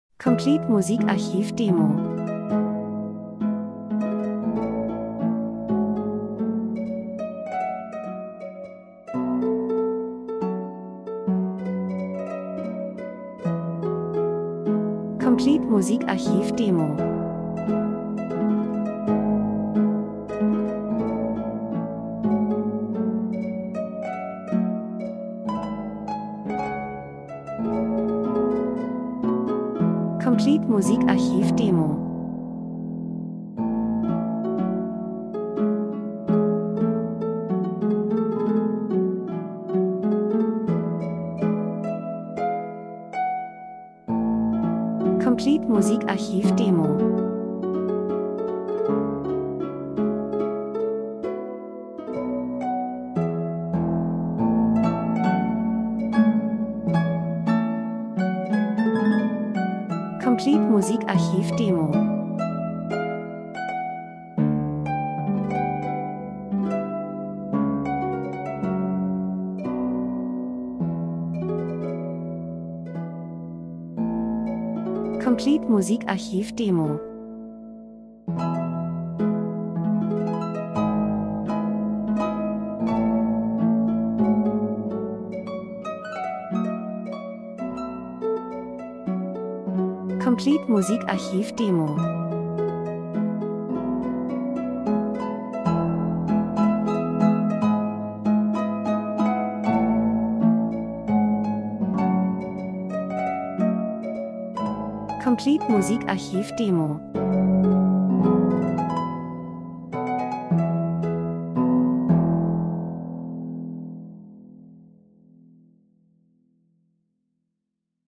1924 Wohlgefühl, Schnee fällt, Liebe , Emotionen 01:57